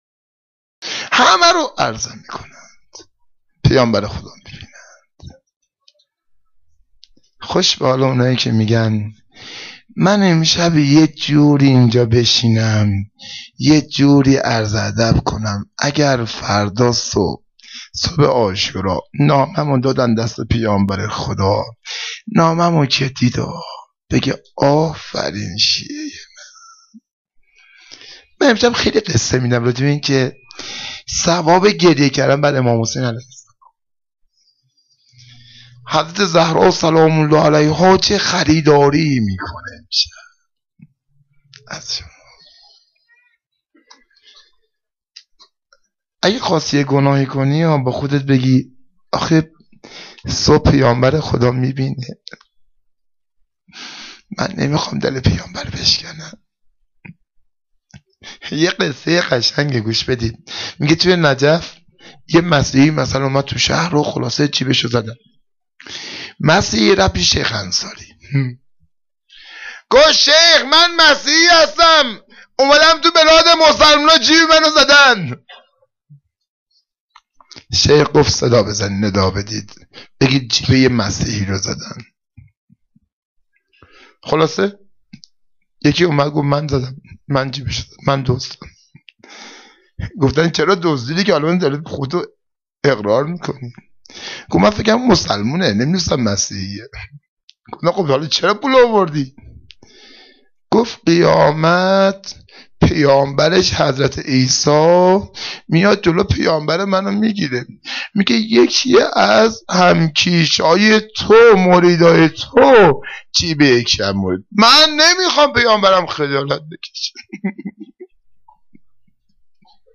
سخنرانی10.2.wma